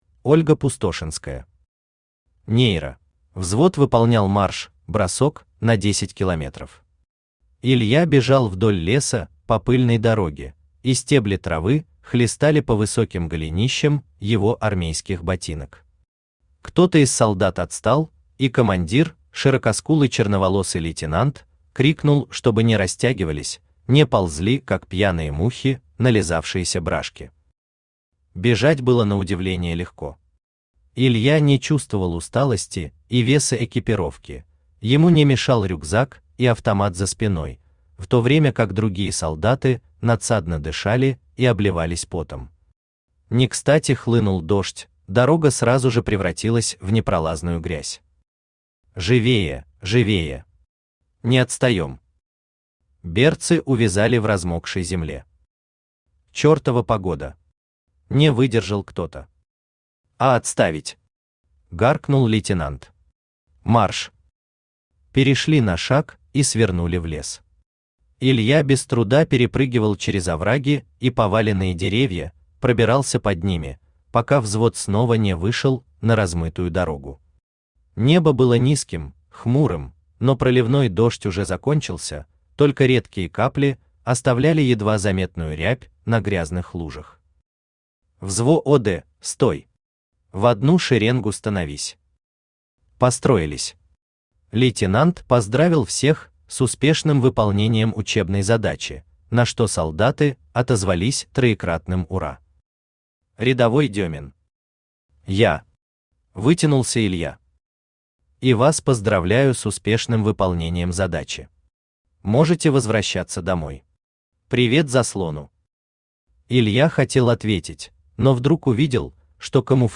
Aудиокнига Нейро Автор Ольга Пустошинская Читает аудиокнигу Авточтец ЛитРес.